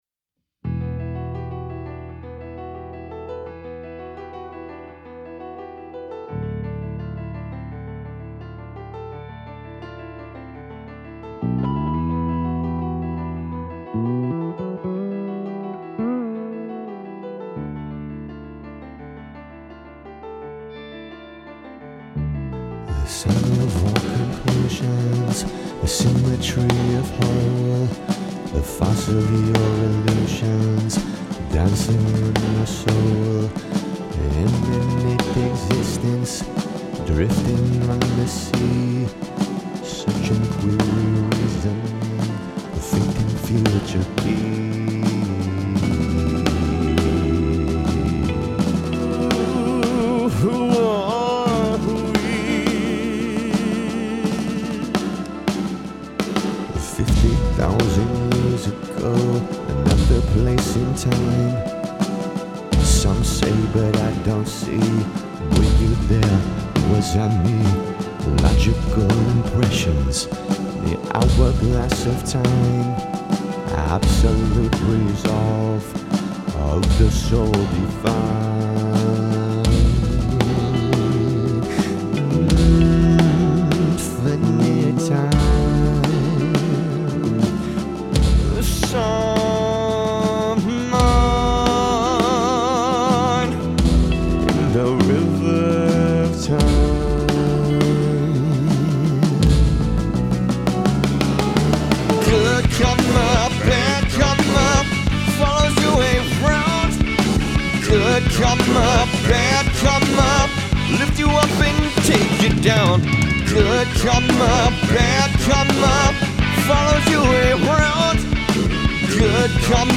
ROCK**